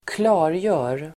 Uttal: [²kl'a:rjö:r]